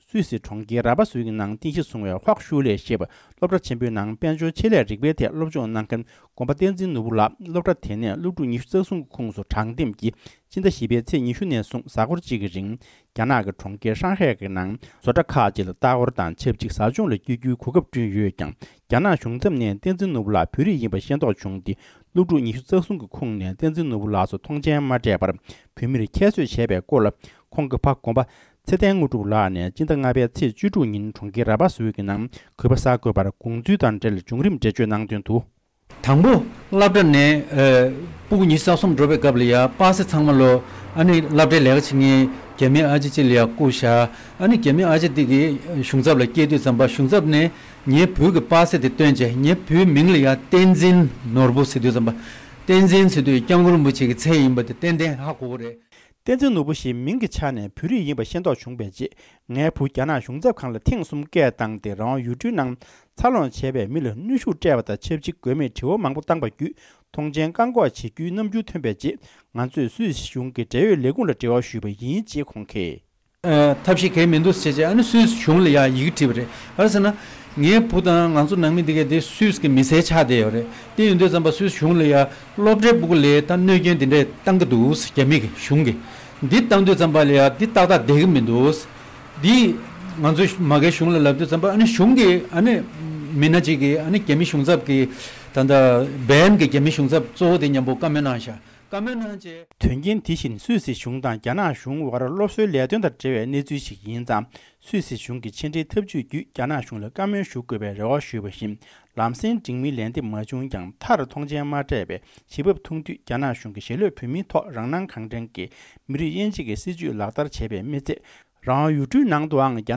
གསར་འགོད་པ